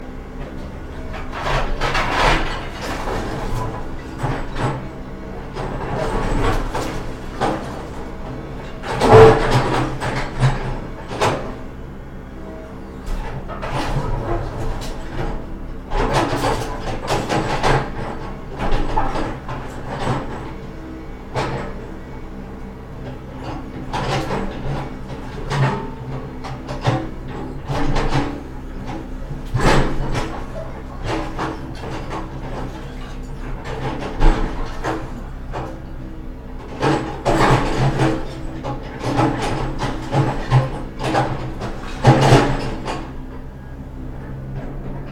针对碗筷声音音效素材的PPT演示模板_风云办公